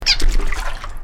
American Bullfrogs, primarily juveniles, produce an alarm call, a fast squeak, which is usually made before a frog jumps into the water to escape from danger.
Sound This is a 1 second recording of the alarm call of a juvenile American Bullfrog jumping into an irrigation canal in Sacramento County during daylight (shown to the right.)
rcatesbeianachirp406.mp3